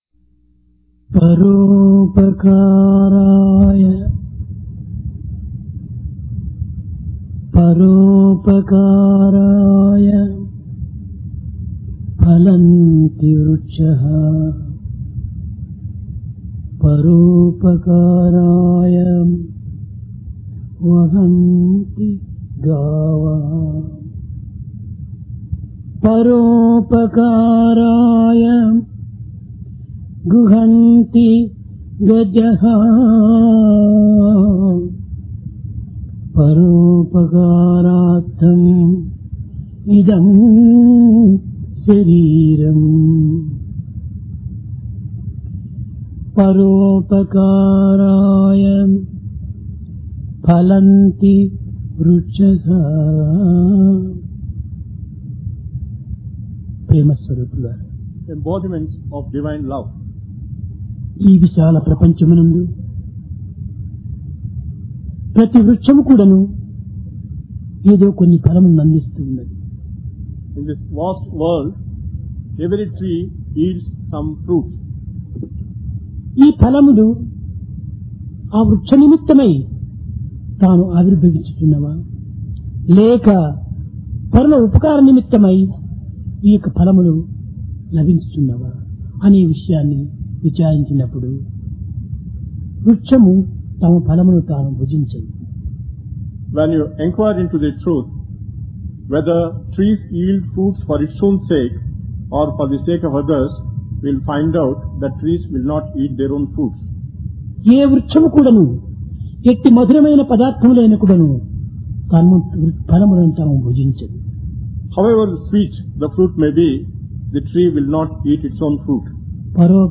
Discourse